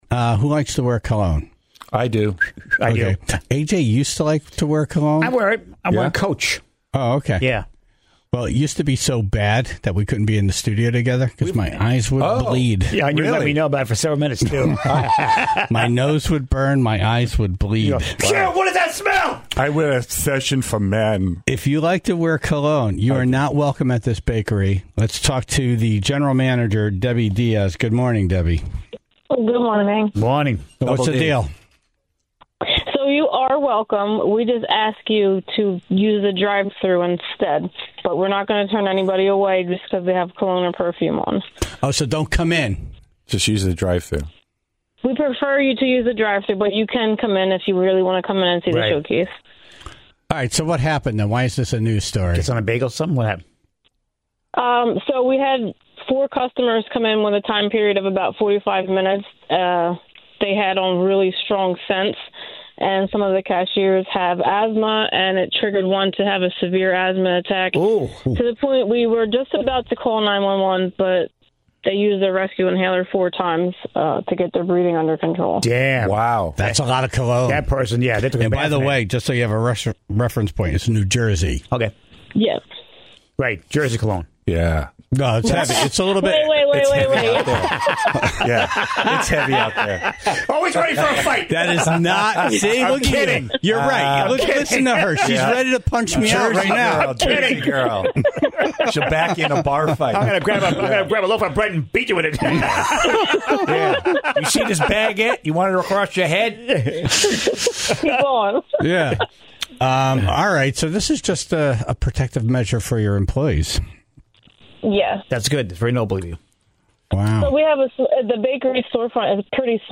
(0:00) In Dumb Ass News, the very real sounds of a very real competitive seagull call tournament.